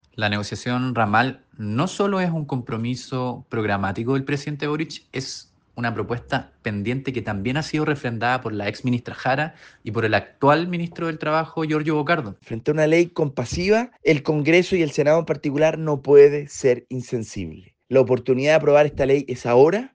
Frente a este escenario, los diputados Andrés Giordano (FA) y Vlado Mirosevic (PL) apuntaron a avanzar en estas materias.